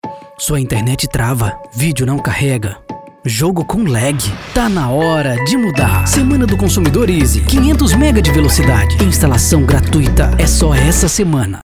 Demo VSL :